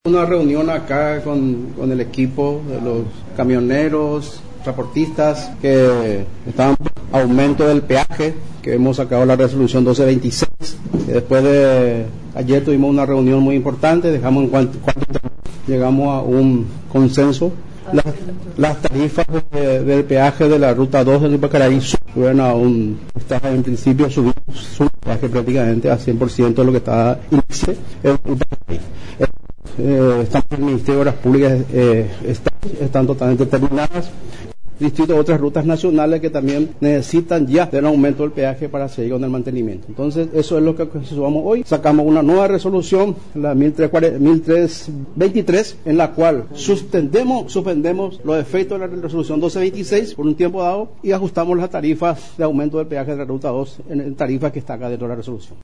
Tras la finalización de la reunión que mantuvo con representantes del sector de camioneros y transportistas, el ministro de Obras Públicas y Comunicaciones, ingeniero Rodolfo Segovia, anunció la reducción en el precio del peaje en el puesto de Ypacaraí.